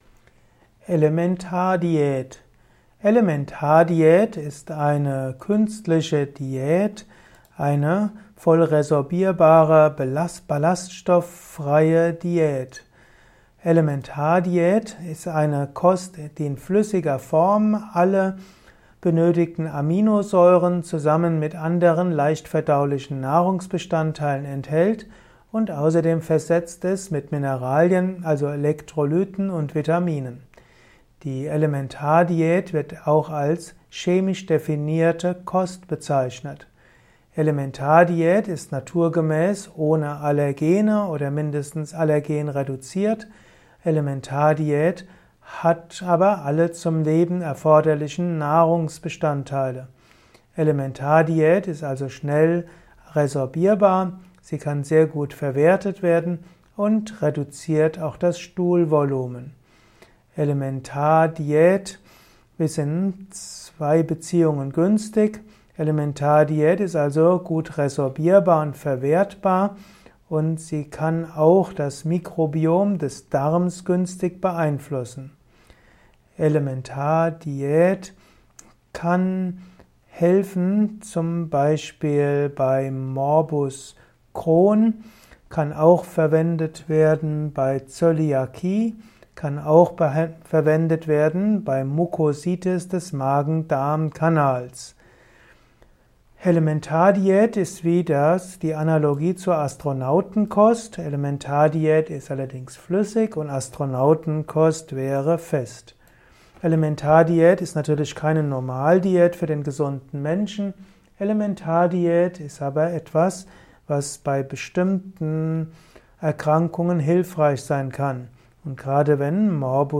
Ein Kurzvortrag zu der Elementardiät